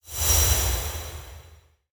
UI_BoneTablet_LightEffect.ogg